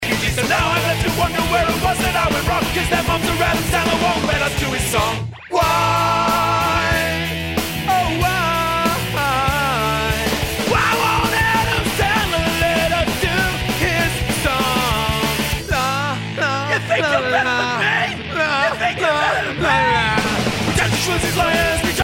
they keep things fast, loud and furious.
The main attraction is a fast, spiteful tune